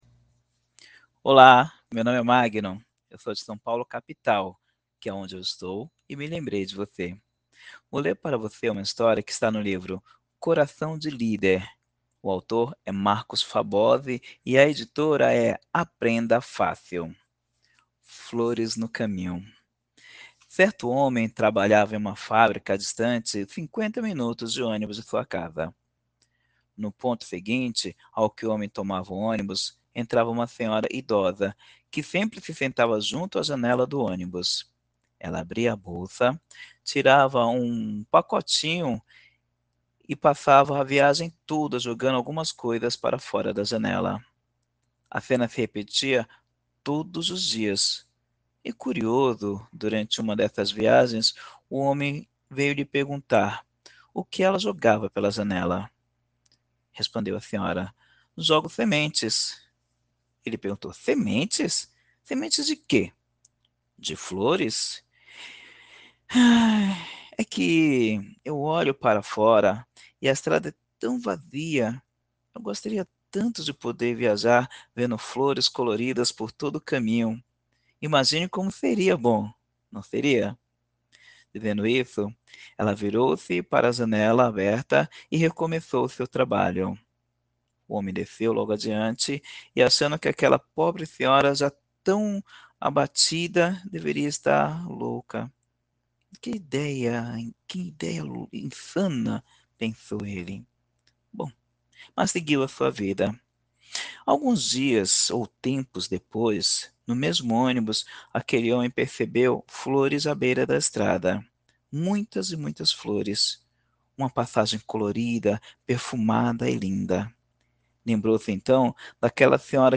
Trecho do livro “Coração de Líder”